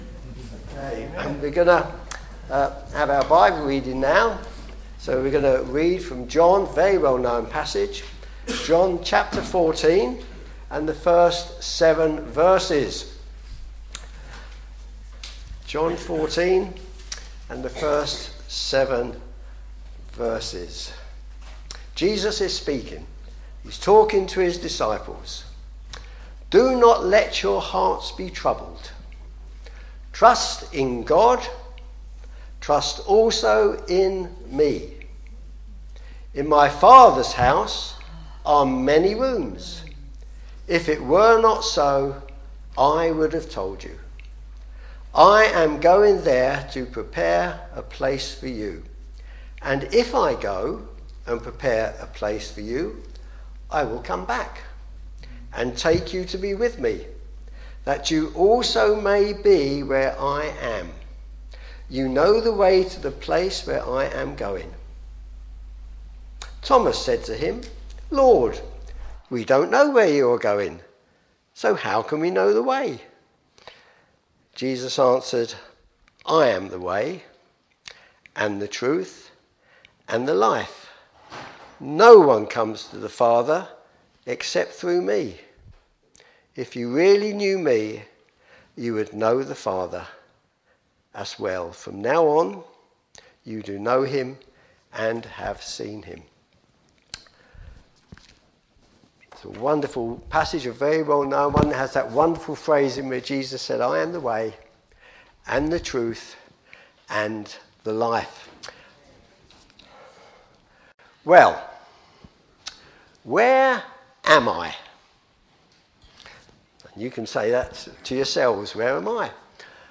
Series: All Age Services, Sunday AM Services